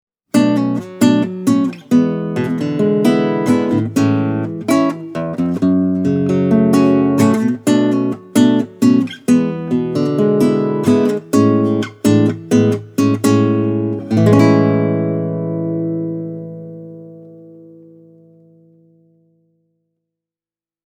The GC3-NAT has a beautiful and fluid voice, a clean and open mid-range, as well as a precise attack.
takamine-gc3-nat.mp3